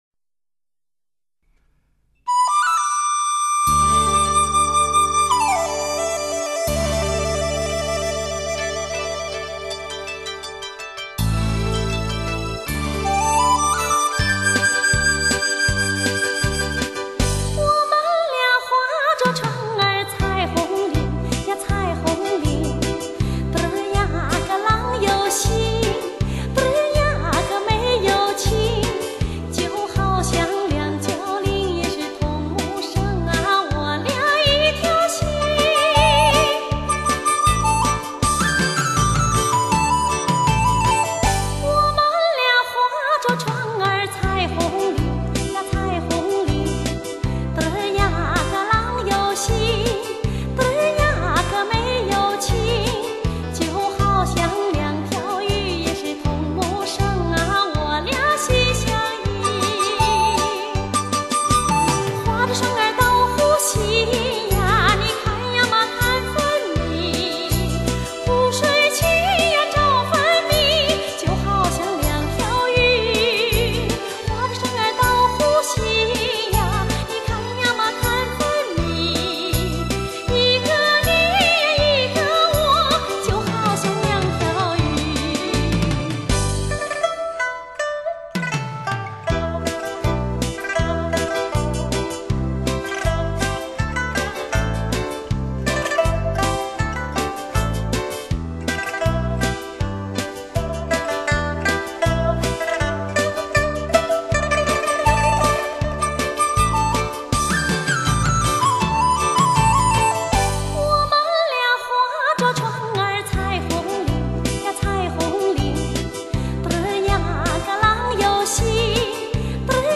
最有磁性的声音 最动听的歌 最动听的歌